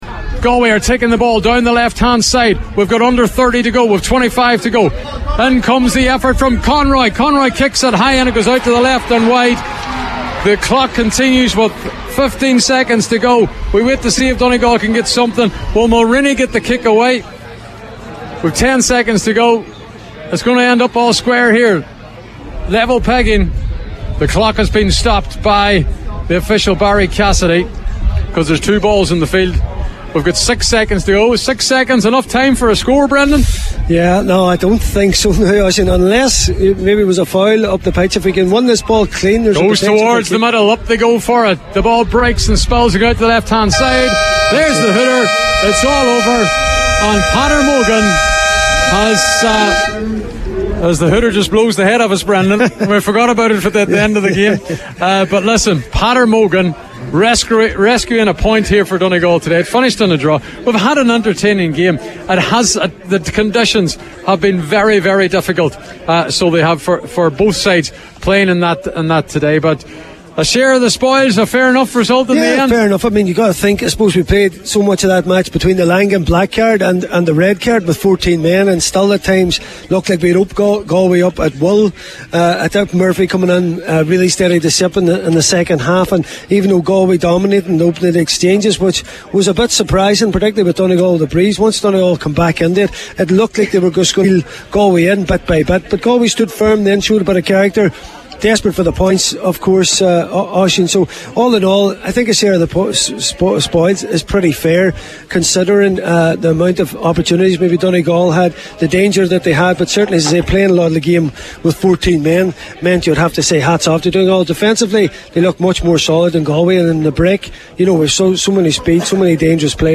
14-man Donegal come from six points down to earn draw against Galway - Post-Match Reaction